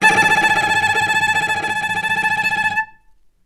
vc_trm-A5-mf.aif